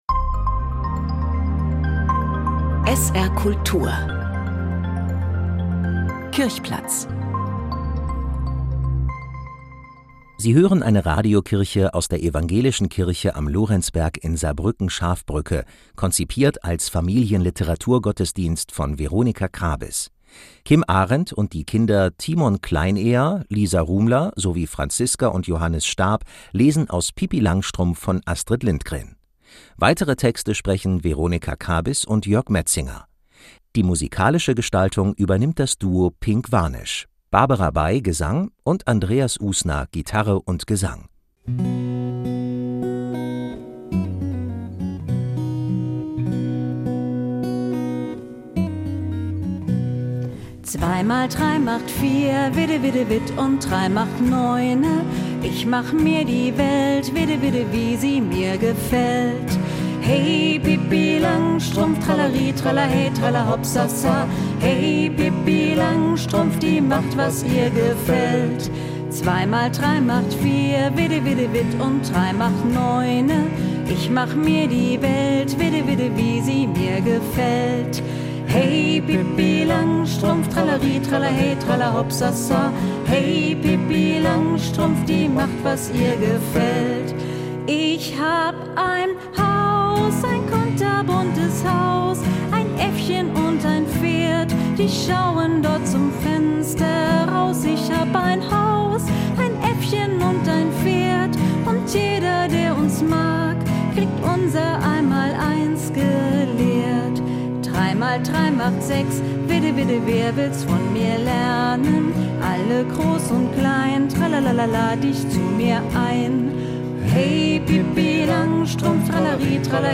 RadioKirche „Pippi Langstrumpf“: Familien-Literaturgottesdienst aus der Ev. Kirche am Lorenzberg, SB-Schafbrücke
Eine Radiokirche aus der Evangelischen Kirche am Lorenzberg in Saarbrücken-Schafbrücke, konzipiert als Familienliteraturgottesdienst
Gitarre und Gesang.